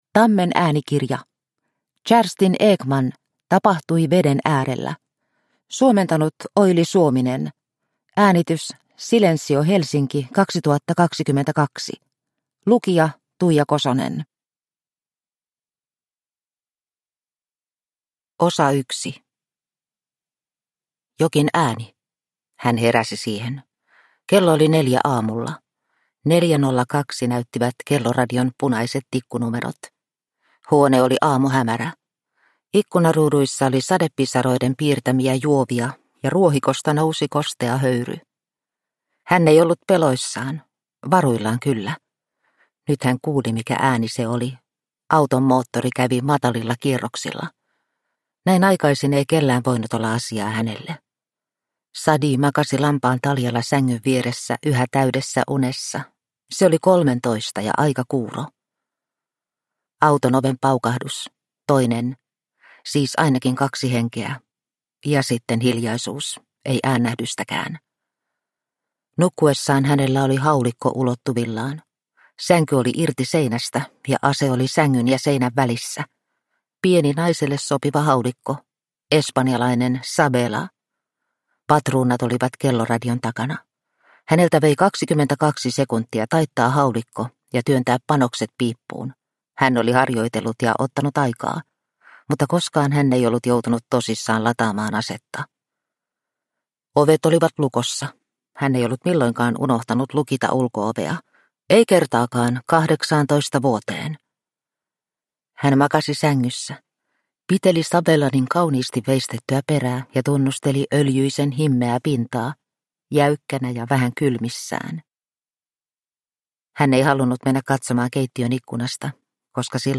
Tapahtui veden äärellä – Ljudbok – Laddas ner